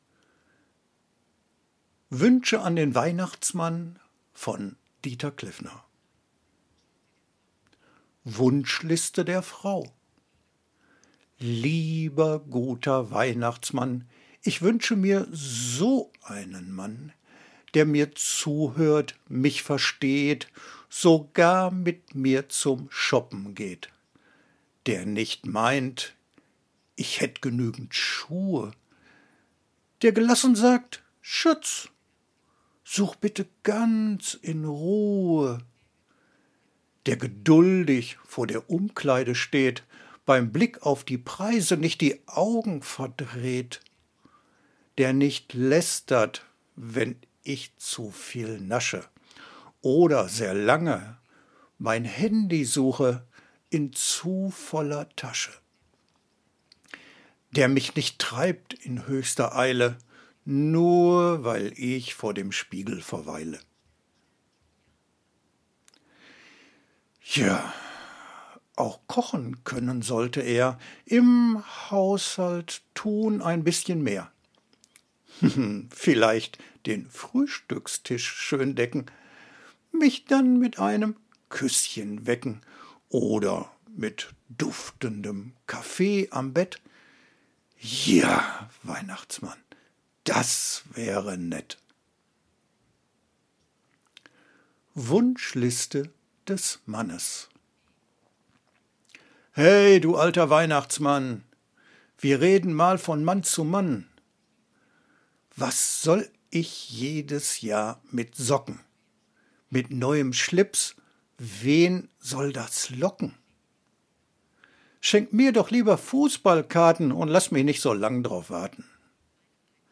von den BLAutoren hat uns das humoristisches Weihnachtsgedicht „Wünsche an den Weihnachtsmann“ für Sie zur Verfügung gestellt.